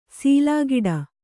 ♪ sīlāgiḍa